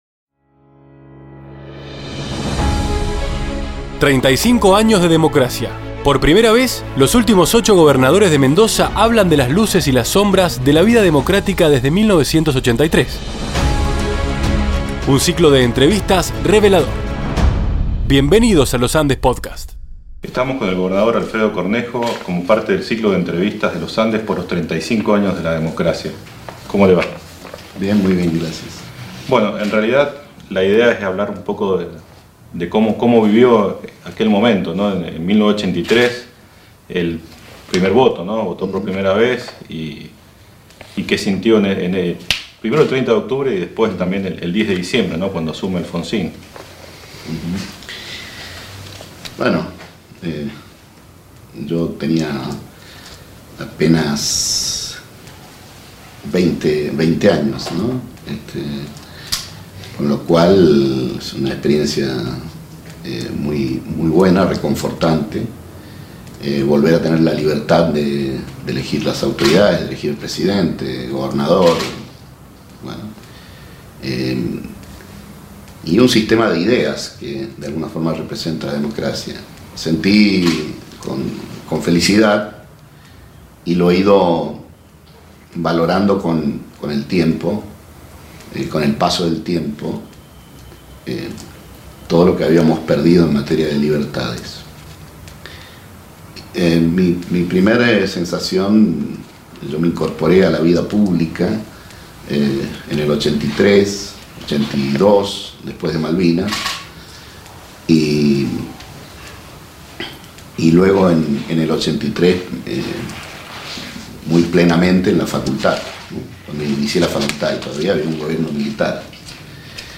35 AÑOS DE DEMOCRACIA: entrevista a Alfredo Cornejo